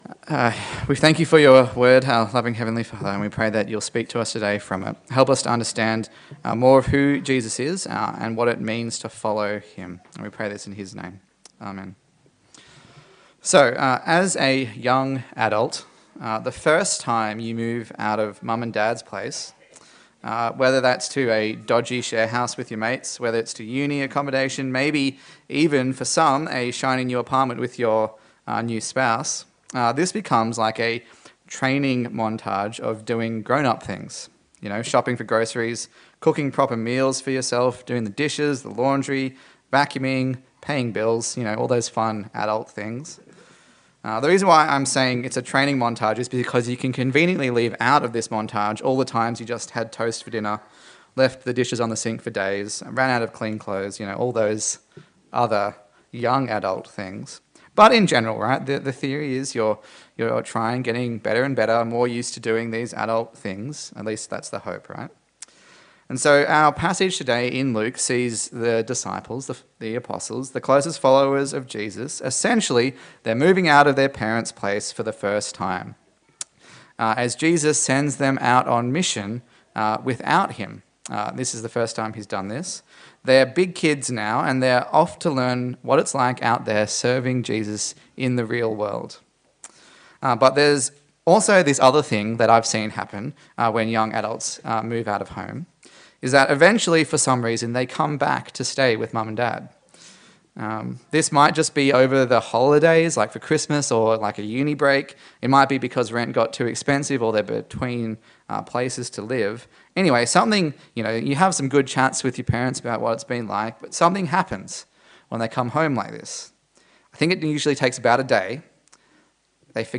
A sermon in the series on the Gospel of Luke
Service Type: Sunday Service